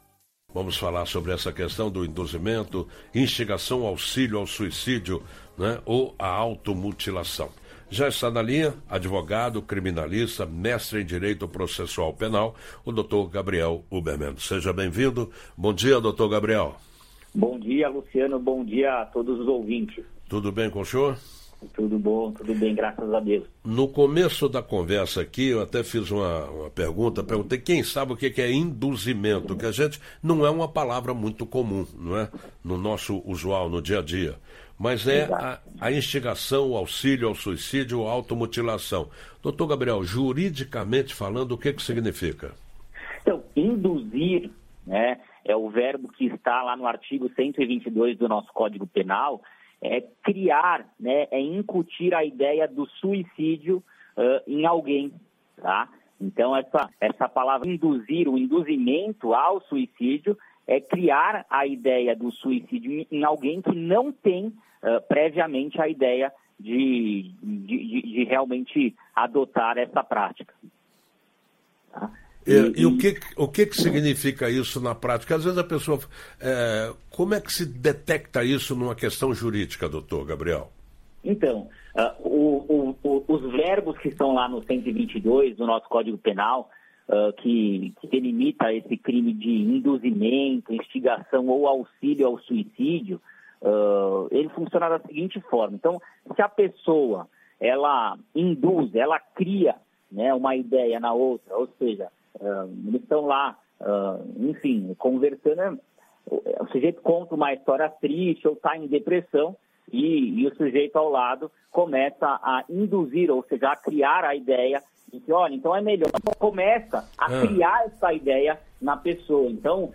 Quem explica mais sobre o tema é o advogado criminalista